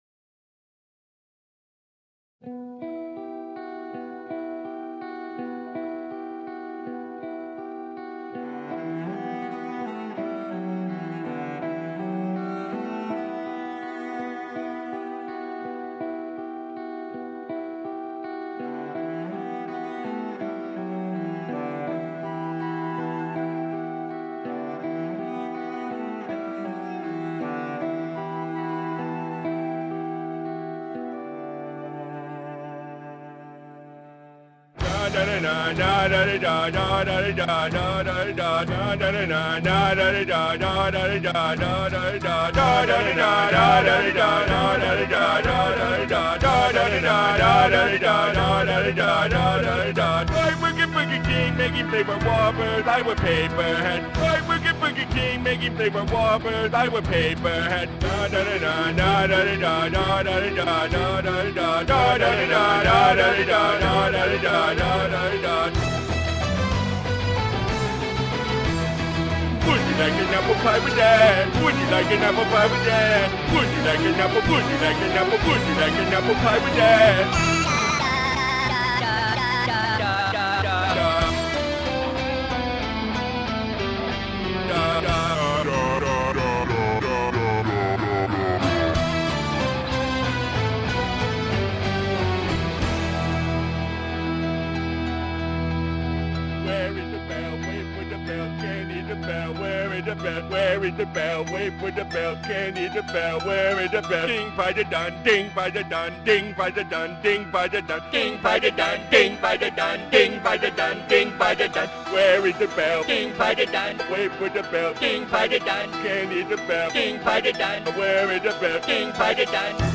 warm Christmas Carol
remix